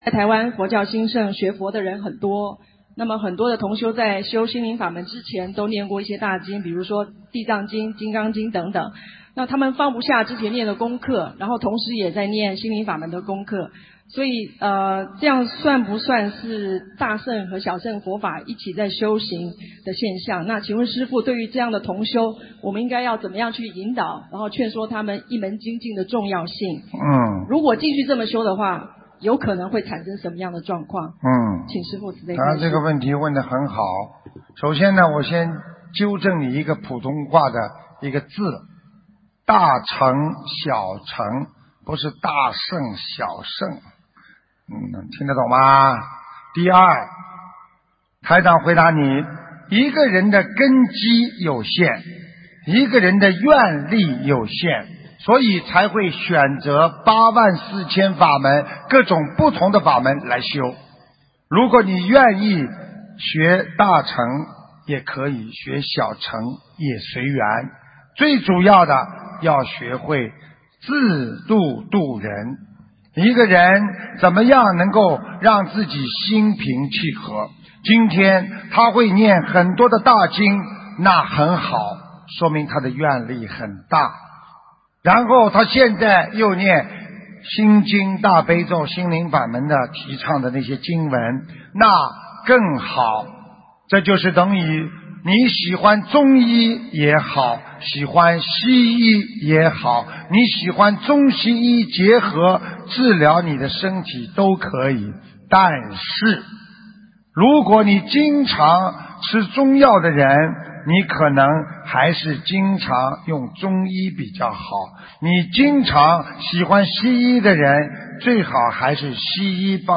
(～2015年6月20日中国·香港fo友见面会共修组提问)